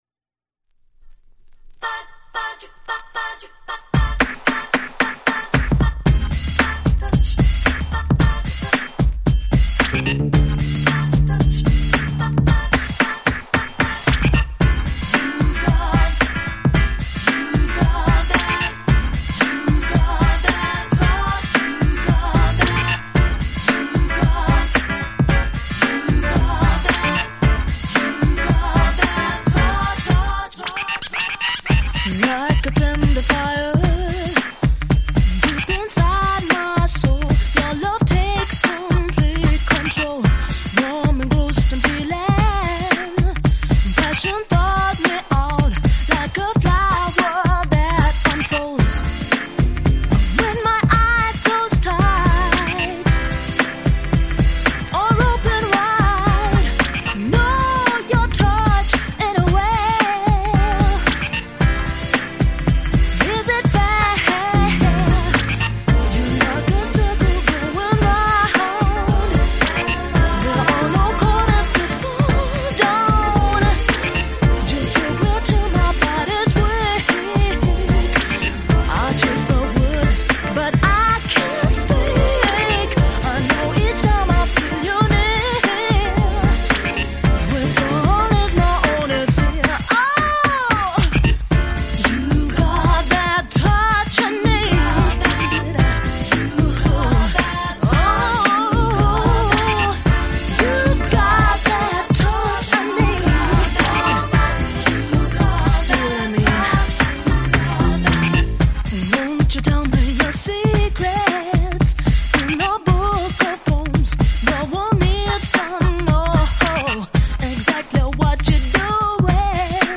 Early House / 90's Techno
(Swing Mix)